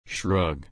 /ʃɹʌɡ/